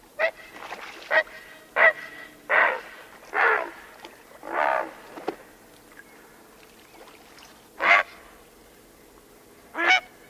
大蓝鹭鸣叫声 呱呱声